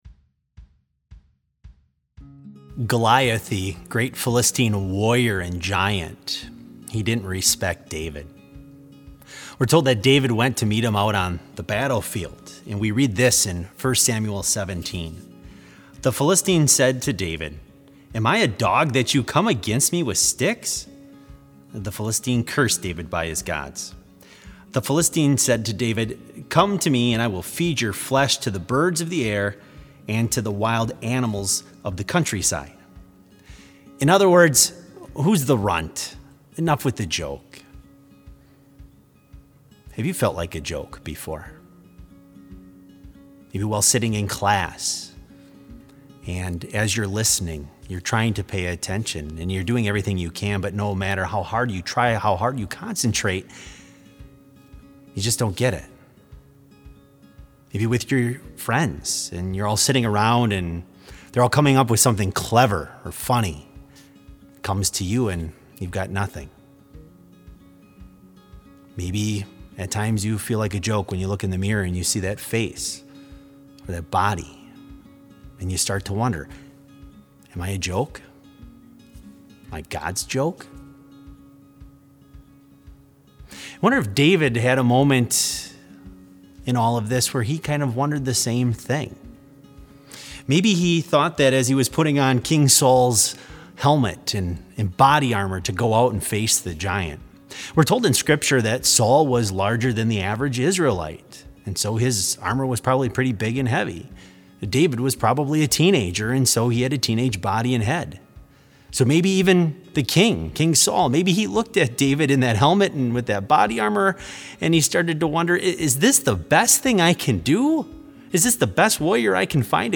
Complete service audio for BLC Devotion - May 1, 2020